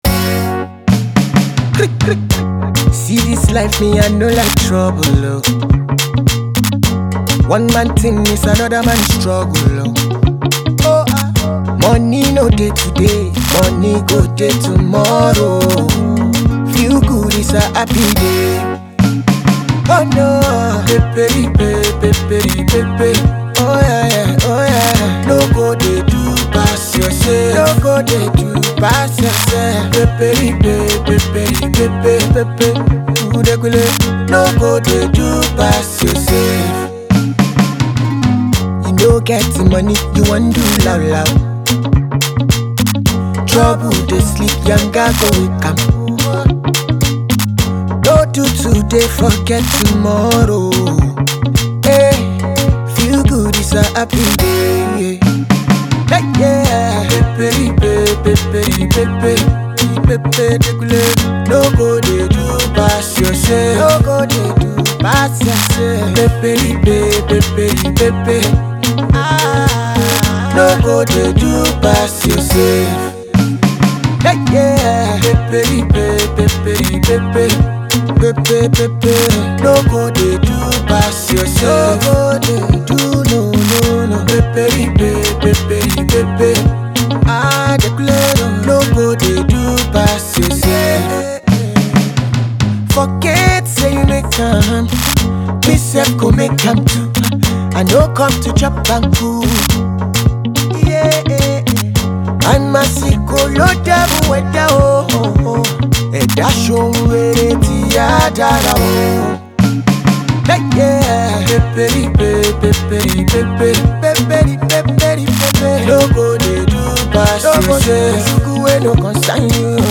A sweet vibe with relatable lyrics